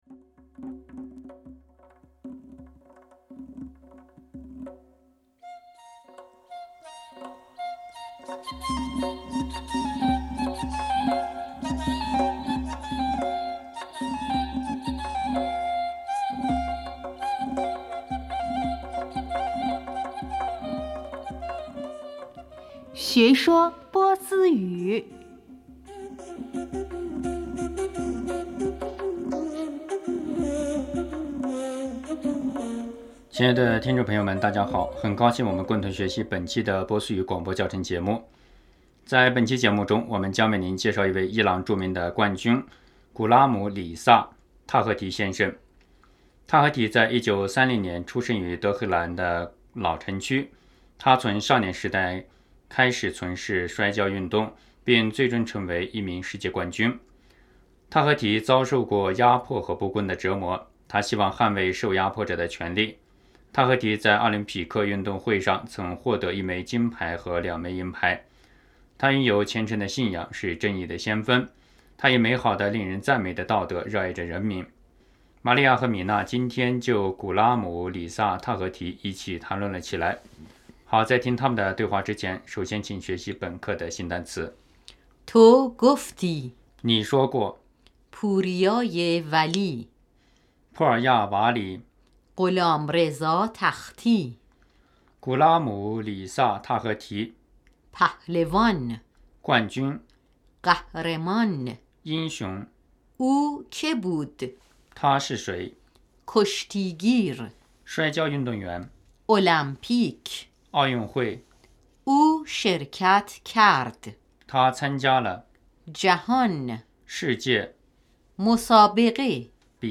亲爱的听众朋友们大家好。很高兴我们共同学习本期的波斯语广播教程节目。